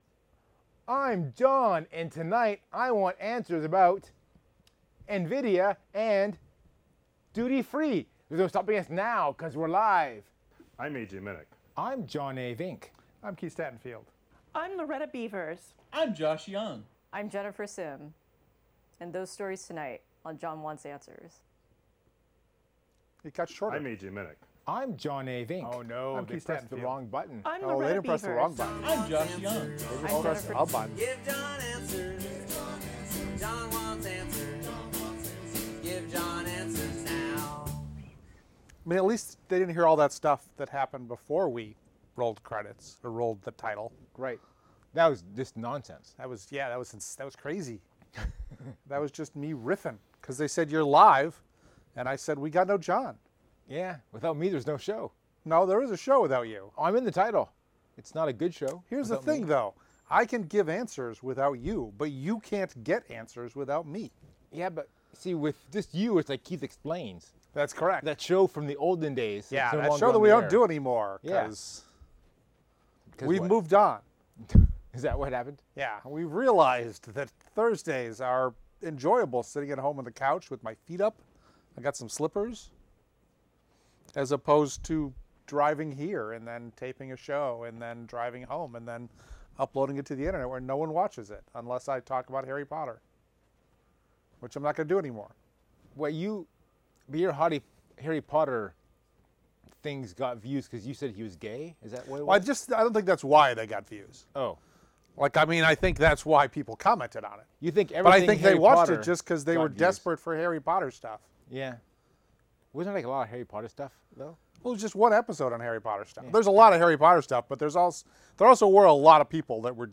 This is the audio version of the video podcast.